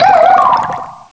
pokeemerald / sound / direct_sound_samples / cries / frillish.aif